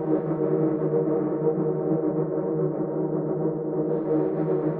SS_CreepVoxLoopA-08.wav